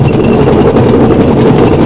tread.mp3